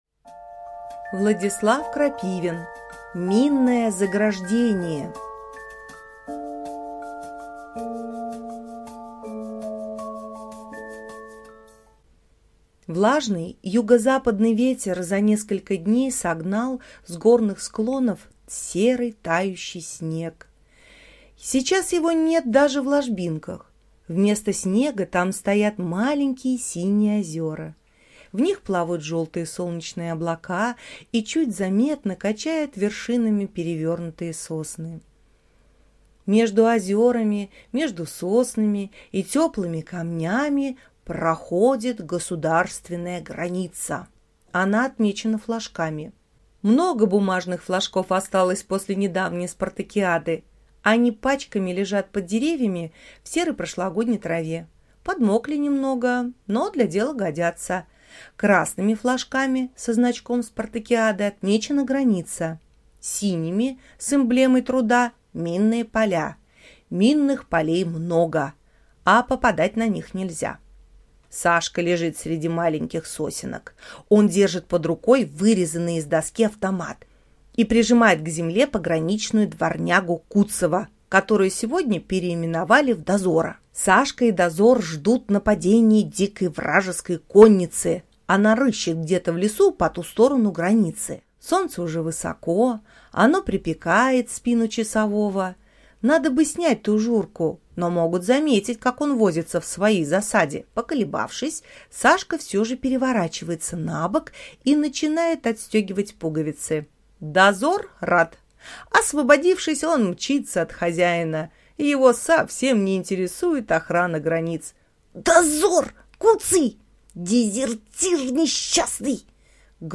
На данной странице вы можете слушать онлайн бесплатно и скачать аудиокнигу "Минное заграждение" писателя Владислав Крапивин.